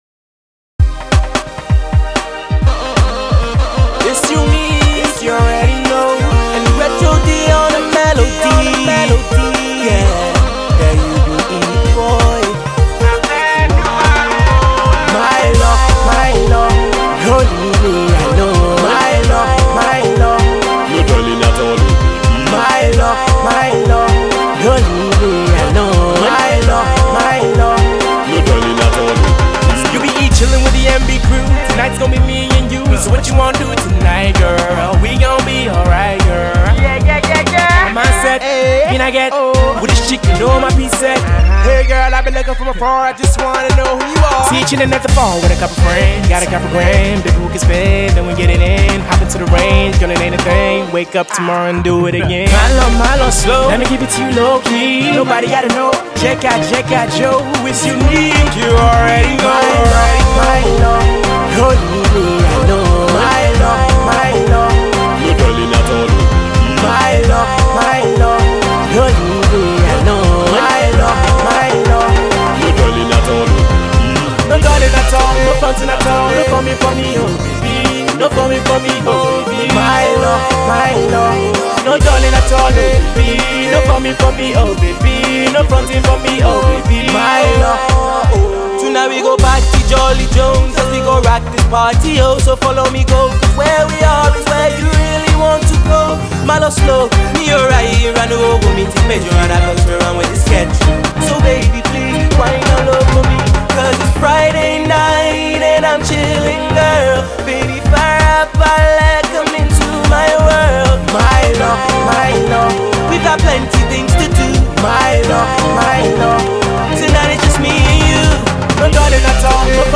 catchy fun Afro-Dance track